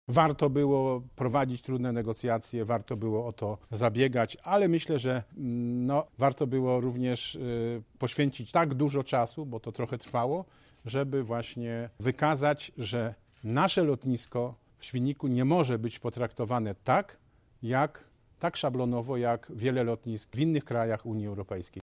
Walka o to, by lotnisko nie musiało zwracać pieniędzy trwała prawie dwa lata. Jednak marszałek zaznaczył, że warto było podjąć ten trud: